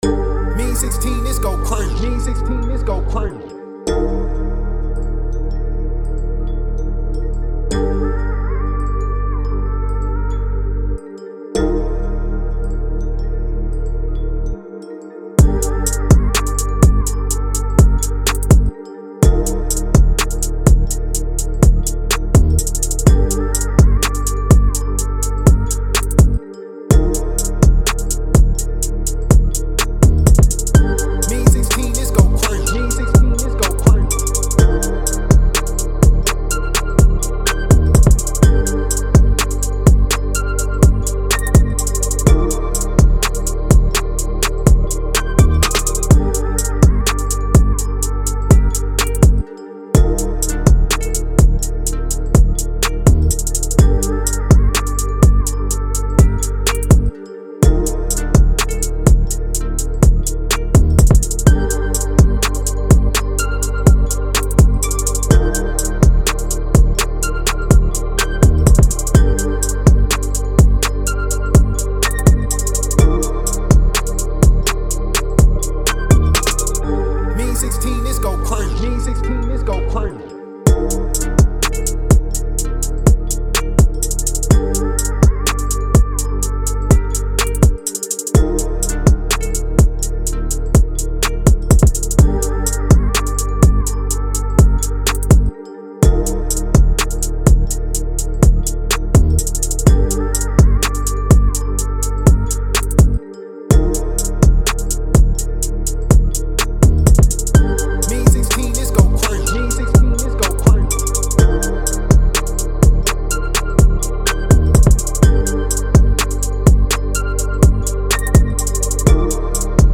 G#-Maj 125-BPM